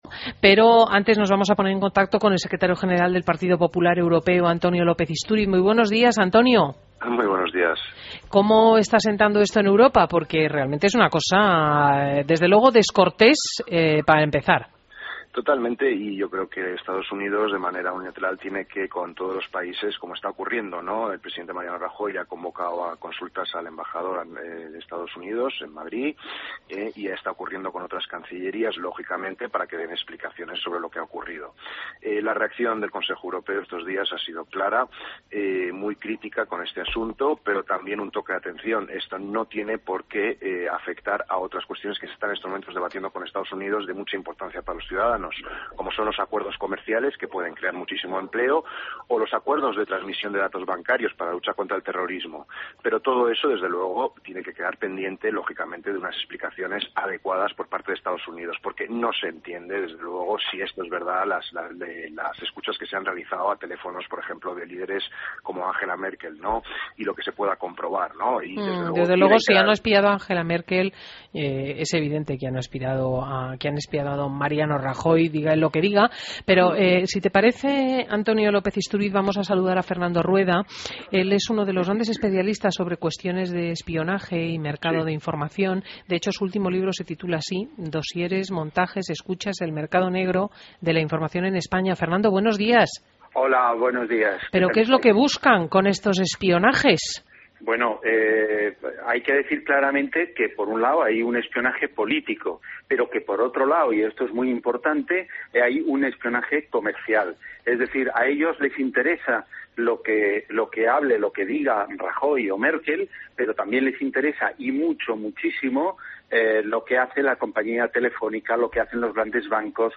AUDIO: Entrevista a López Isturiz en Fin de Semana COPE